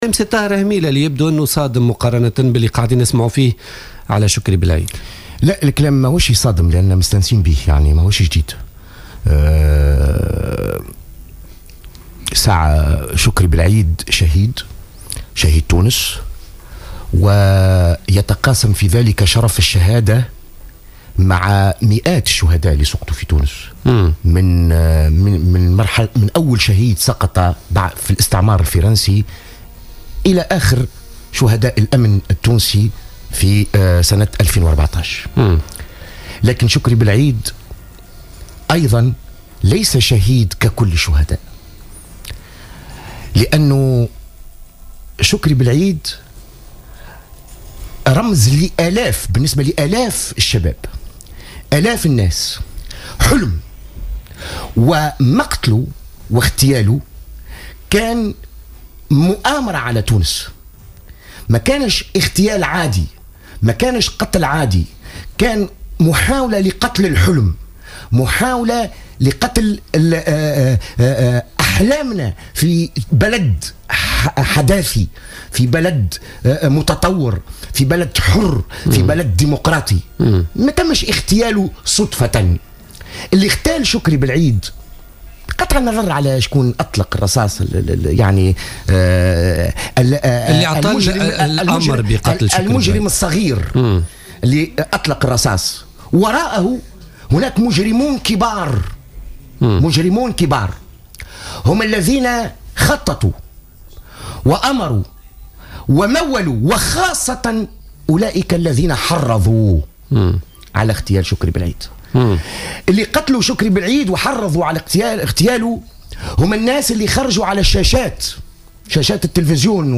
قال المنسق العام لشبكة دستورنا جوهر بن مبارك ضيف بوليتيكا اليوم الجمعة 6 فيفري 2015...